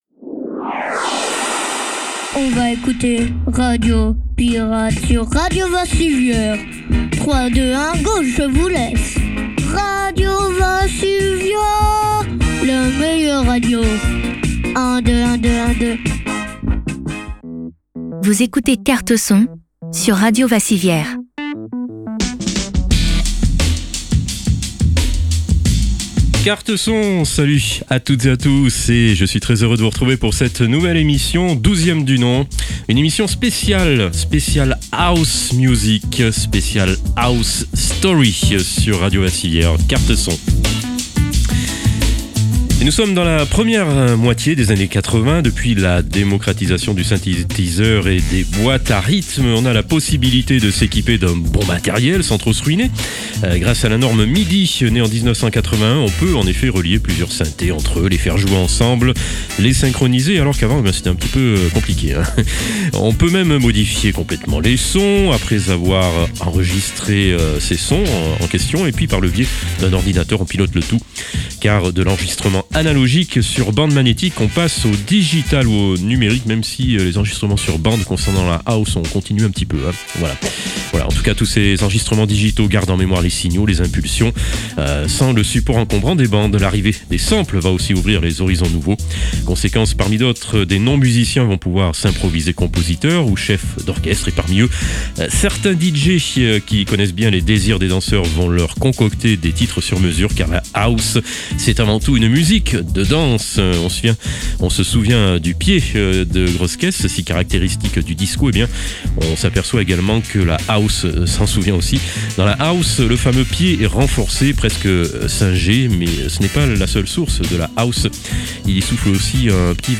Good vibes !